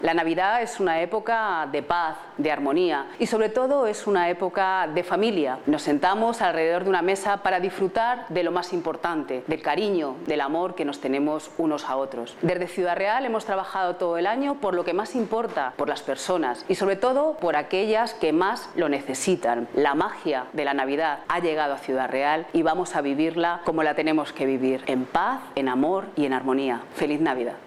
La alcaldesa de Ciudad Real, Pilar Zamora, ha querido felicitar la Navidad y el nuevo año a todos los vecinos y vecinas de Ciudad Real a través de un video grabado hace unos días en el recién inaugurado Museo Elisa Cendrero.